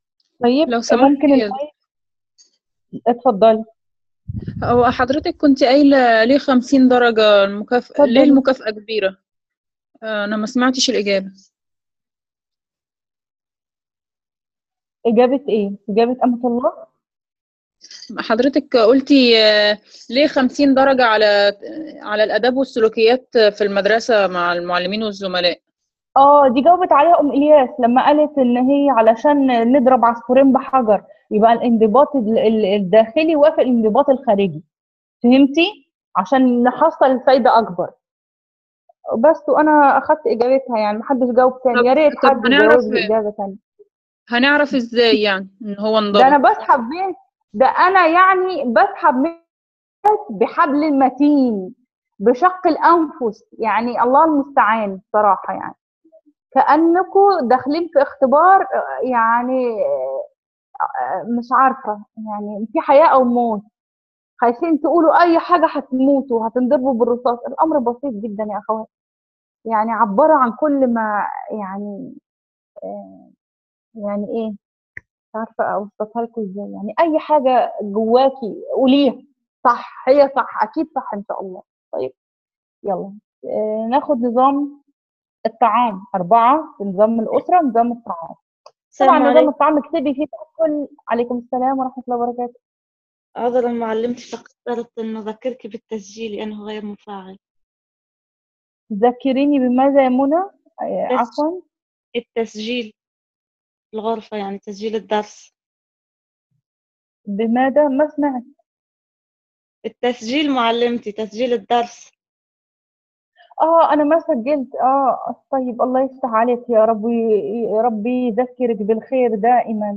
المحاضرة الأولى.wma